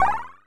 PowerUp2.wav